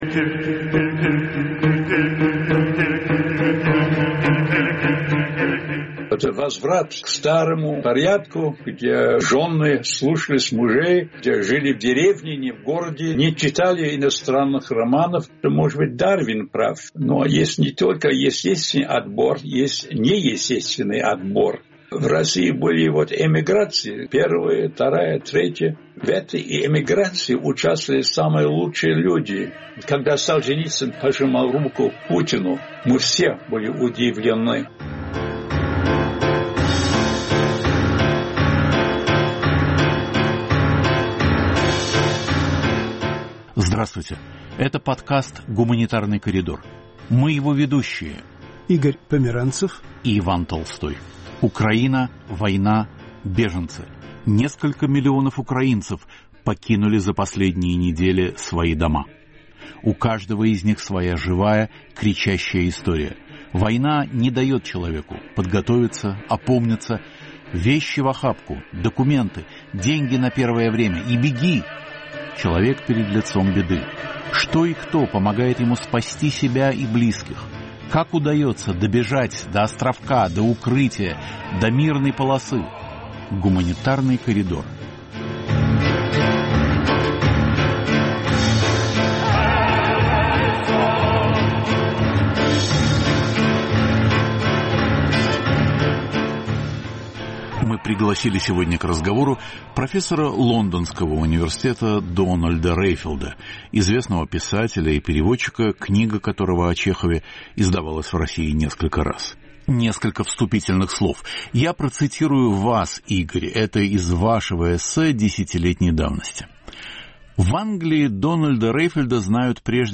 В эфире "Свободы" – британский литературовед и историк, автор книг о Чехове, Сталине и Грузии